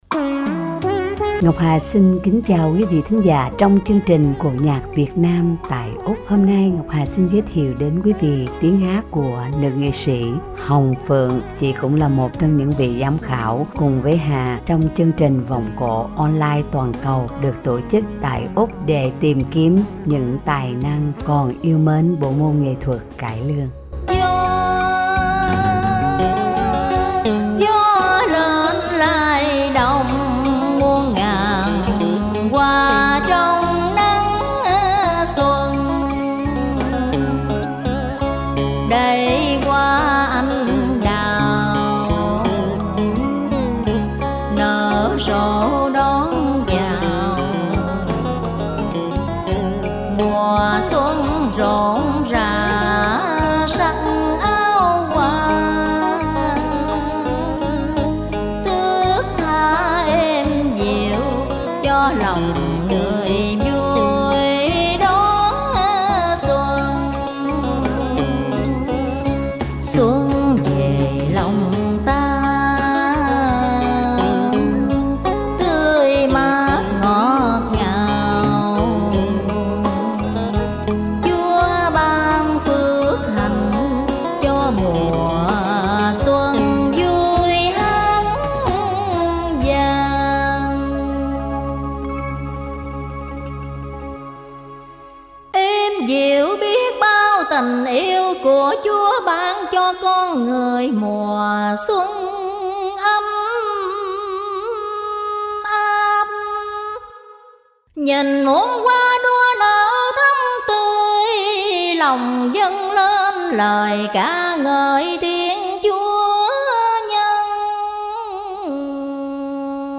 Giọng Ca Vọng Cổ